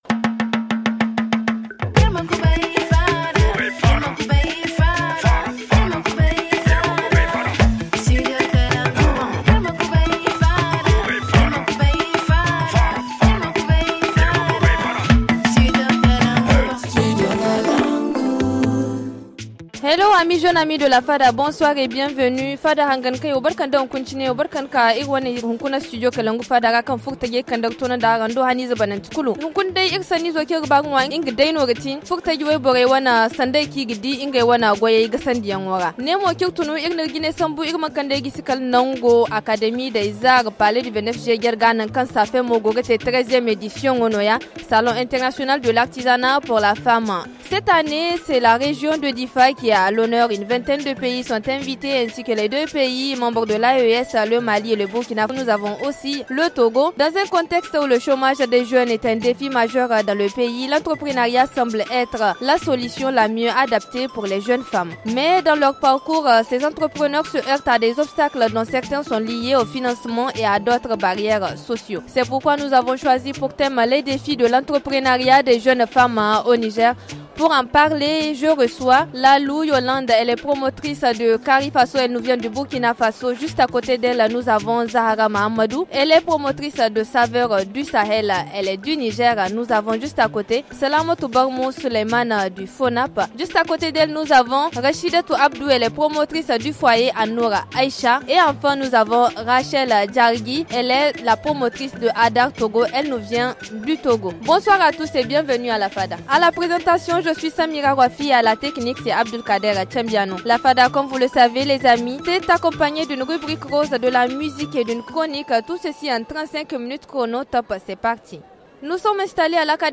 À l’occasion du Salon International de l’Artisanat pour la Femme (SAFEM)